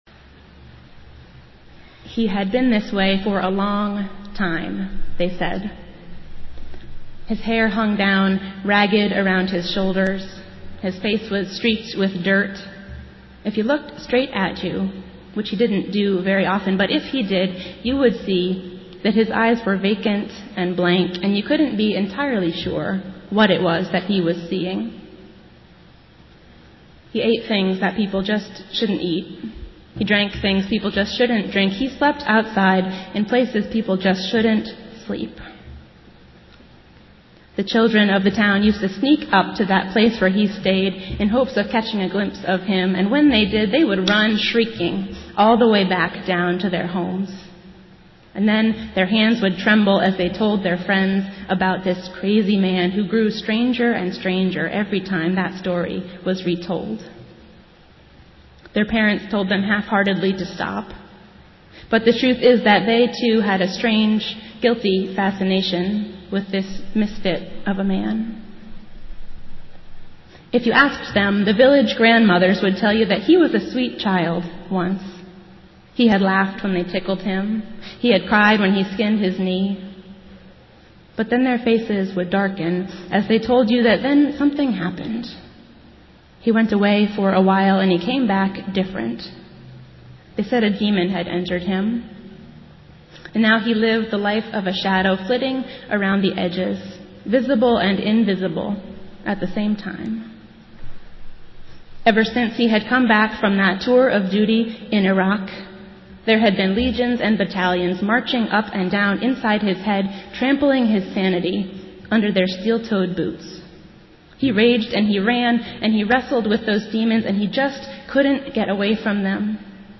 Festival Worship - Fourth Sunday after Pentecost